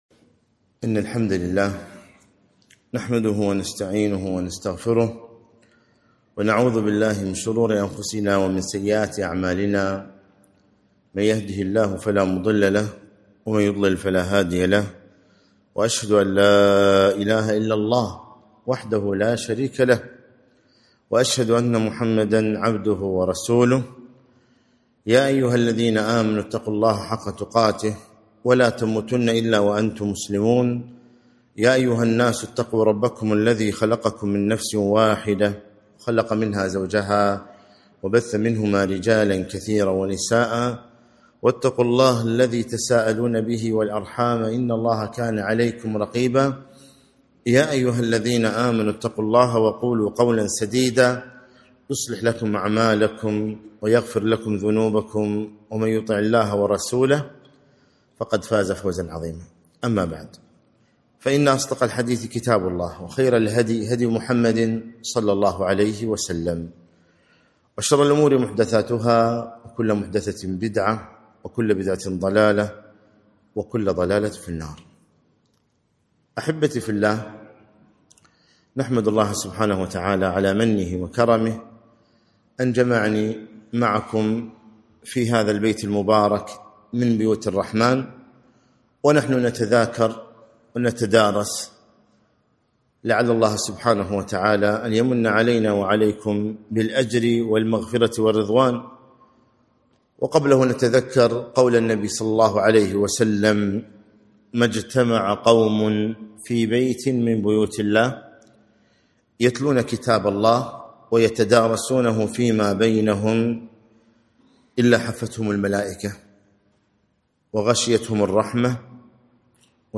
محاضرة - اغتنام الأوقات في الإجازات